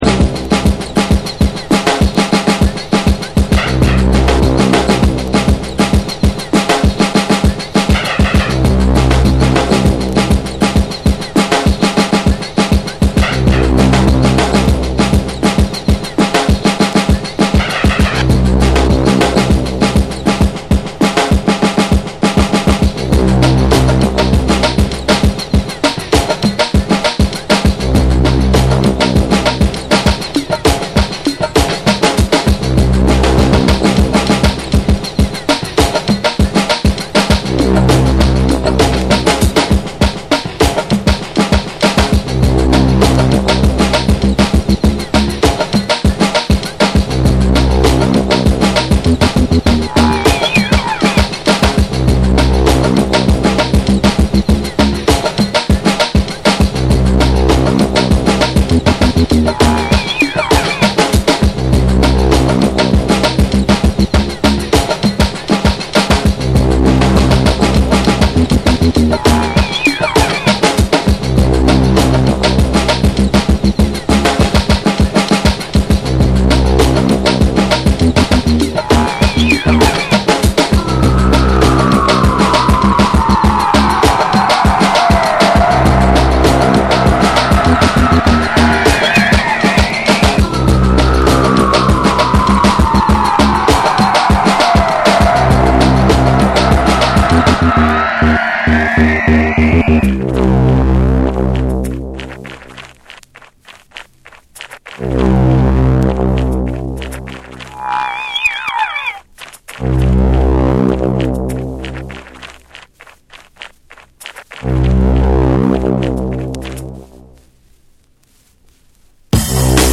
不穏なベースと歪んだビートが交錯する、インダストリアル色も感じさせるエッジの効いた異形ブレイクビーツ
BREAKBEATS